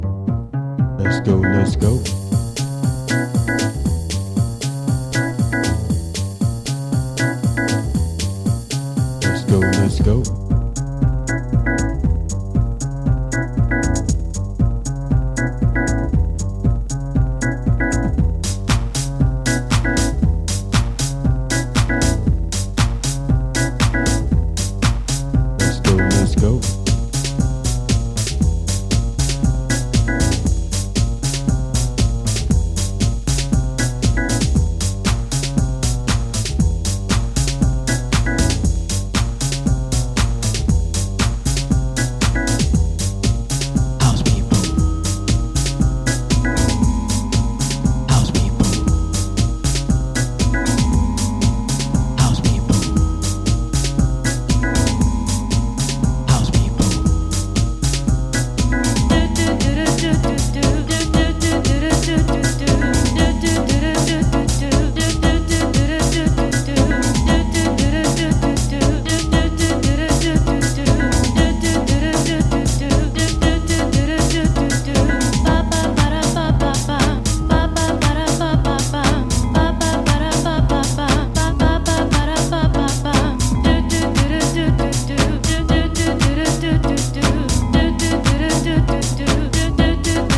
スキャットとアコースティック・ベースがループするスゥインギーな
パーカッションとベース・ラインを軸にドープに展開する激渋のジャズ・ハウス